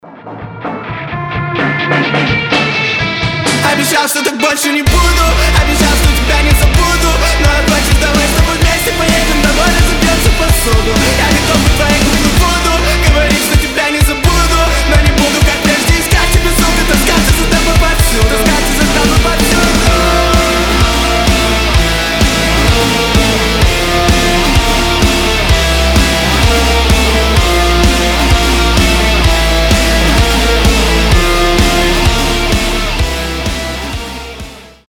• Качество: 320, Stereo
громкие
Alternative Rock